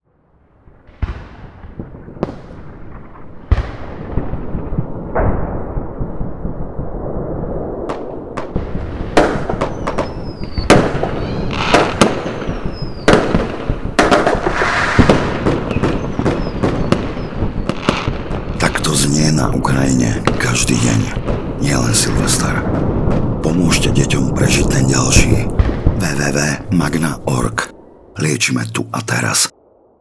Pripomenuli sme Slovákom, že aj počas vianočných sviatkov trpí na Ukrajine kvôli vojne mnoho detí. Využili sme na to zvuky zábavnej pyrotechniky, ktoré sa veľmi ponášajú na zvuky bombardovania.